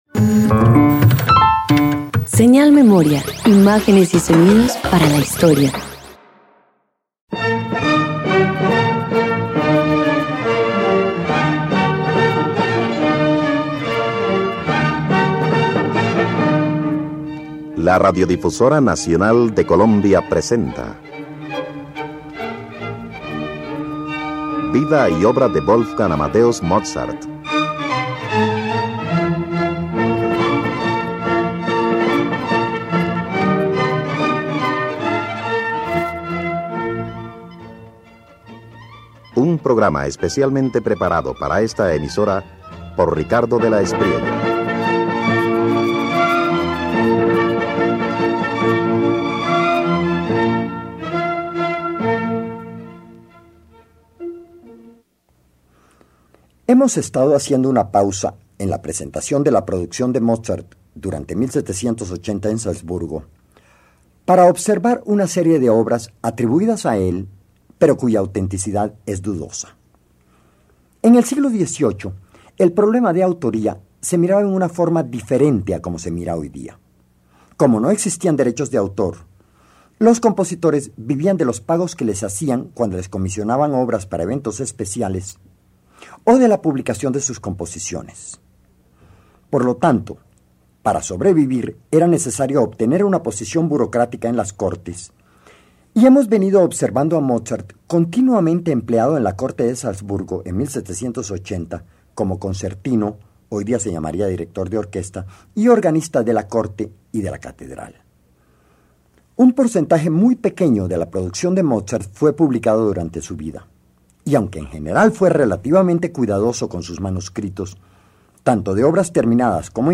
Un análisis sobre la Casación en mi bemol mayor, compuesta por Georg Lickl e interpretado por el Consortium Classicum. Una de las obras para viento atribuidas falsamente al compositor Wolfgang Amadeus Mozart durante su última estadía en Salzburgo.